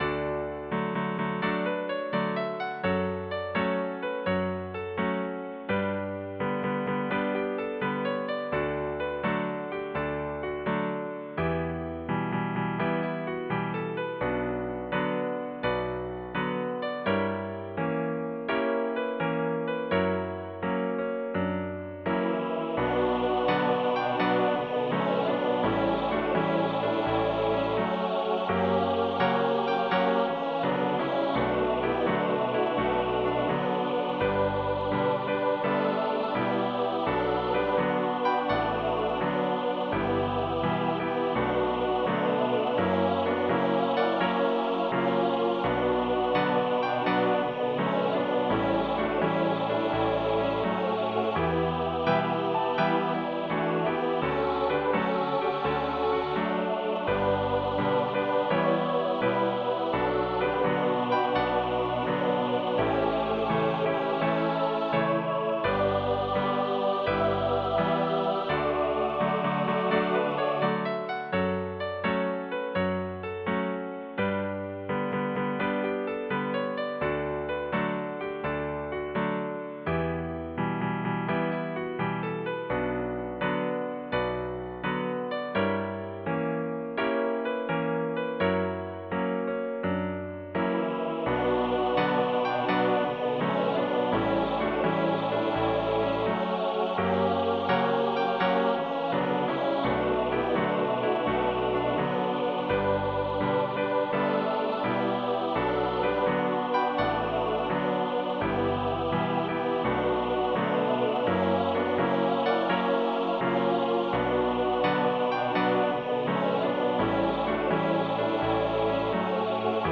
SATB choir and piano setting
Voicing/Instrumentation: SATB